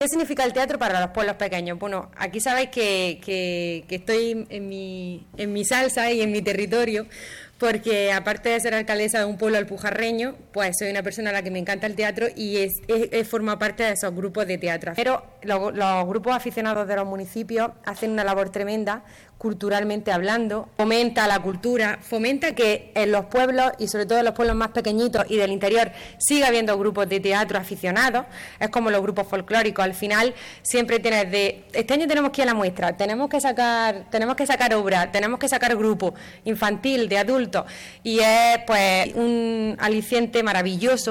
La XXVIII Muestra de Teatro Aficionado de la Alpujarra se ha presentado esta mañana y se celebrará del 4 de noviembre al 9 de diciembre en Pampaneira (Granada)
27-10_muestra_teatro_alpujarra__diputada.mp3